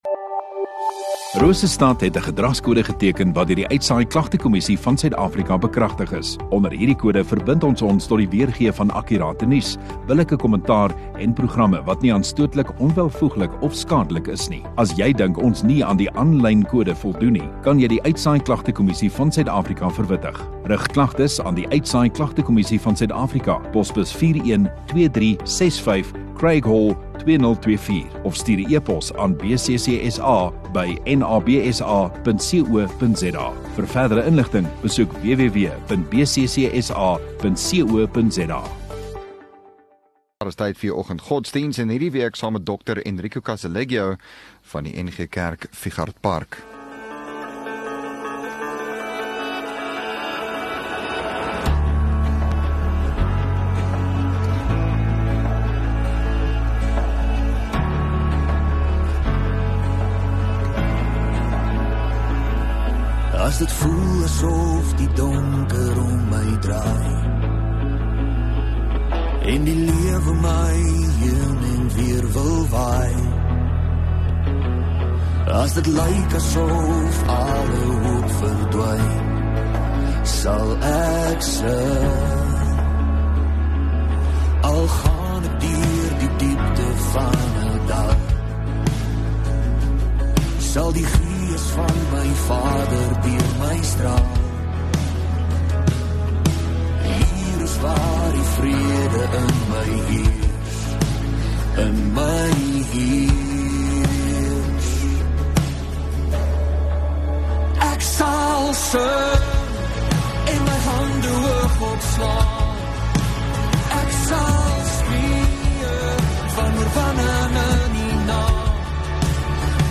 5 May Maandag Oggenddiens